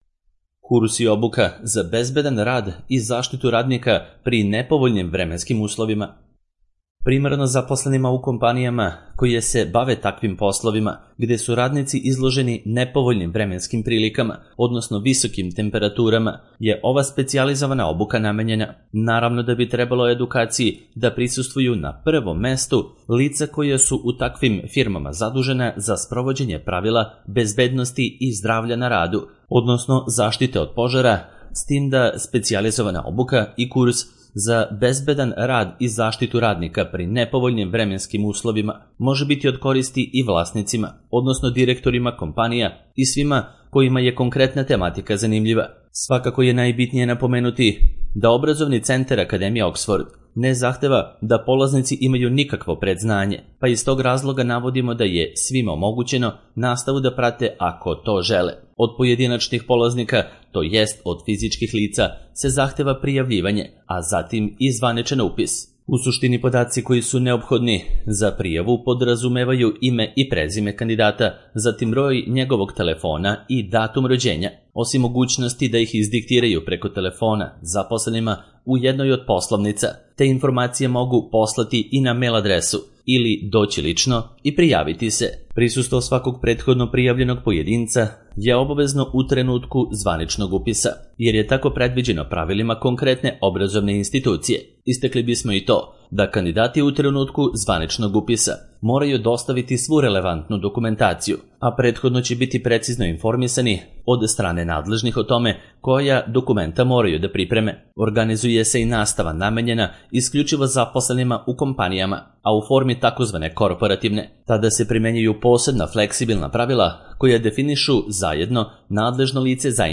Bezbedan rad i zaštitu radnika pri nepovoljnim vremenskim uslovima - Audio verzija